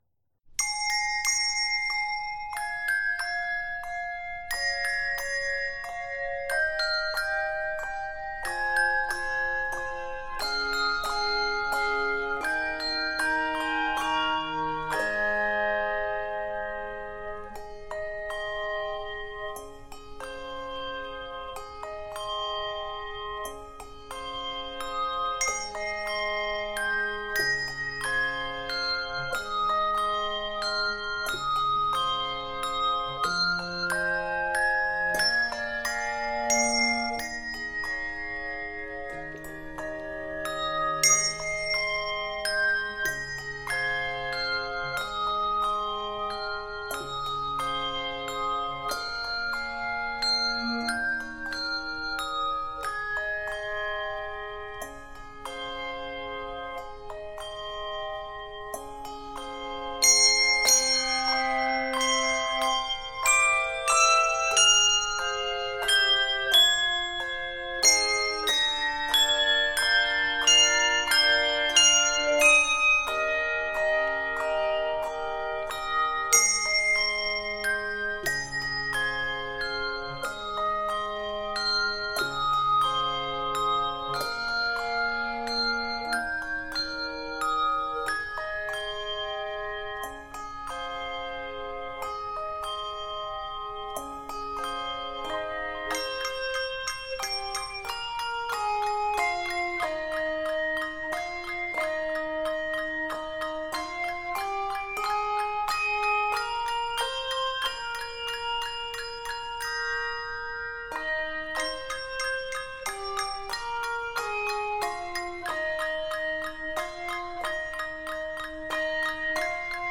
Simple in concept and beautiful to hear